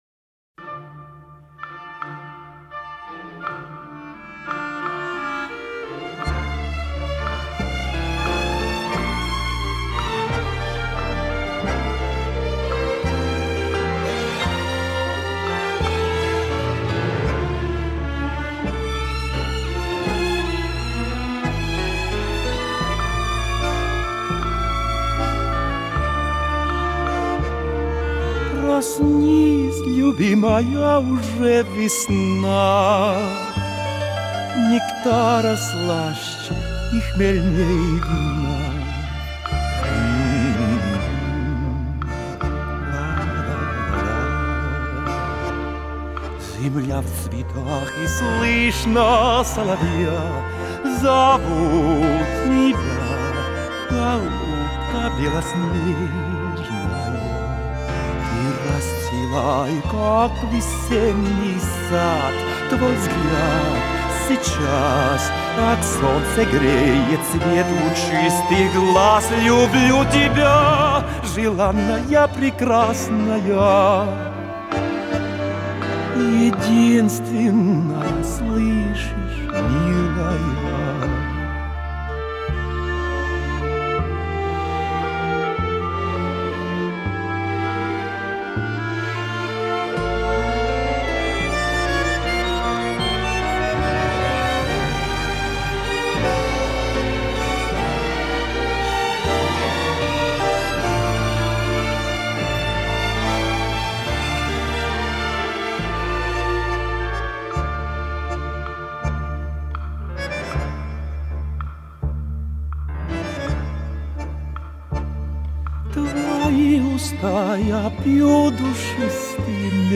Христианская музыка